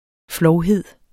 Udtale [ ˈflɒwˀˌheðˀ ]